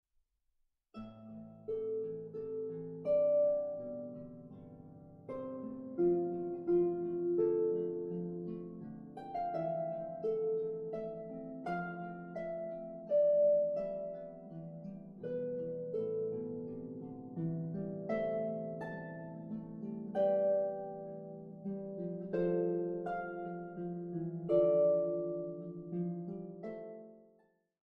solo harp demo files